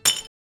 SmallMetalInInventory.ogg